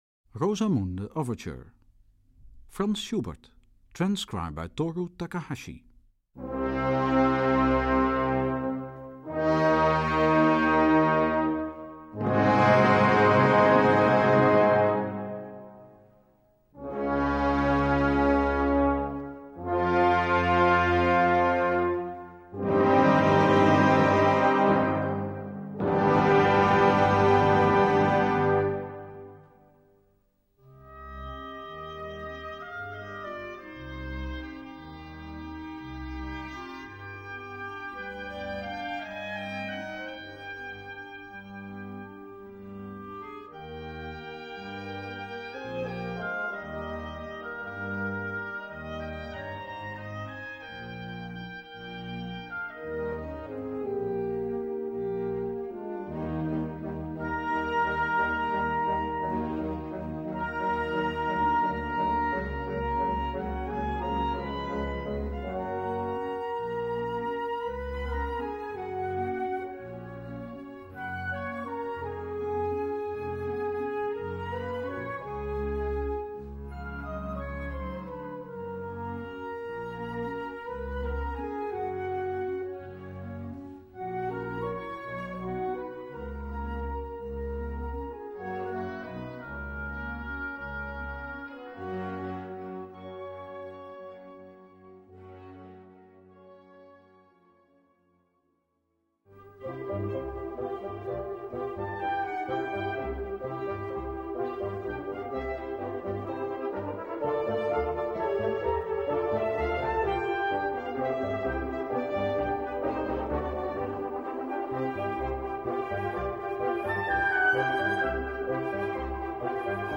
Key: C minor (original key)